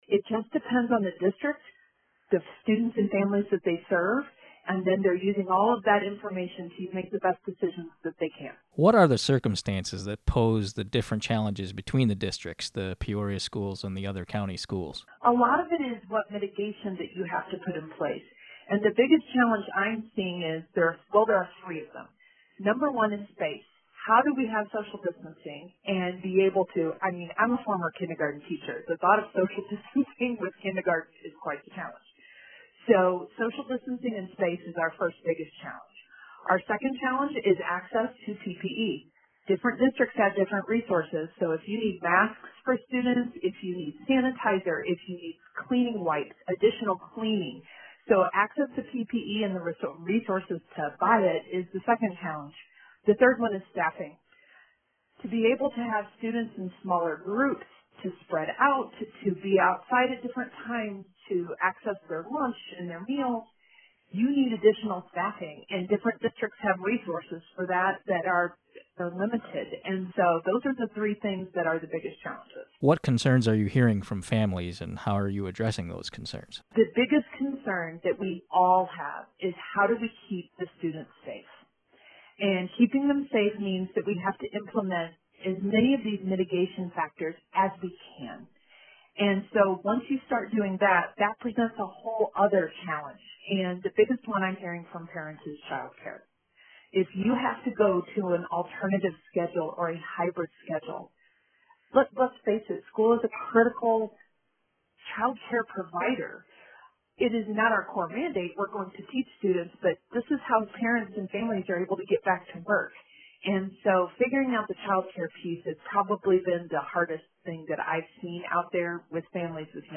WCBU interviews Peoria County Regional Superintendent of Schools Beth Crider.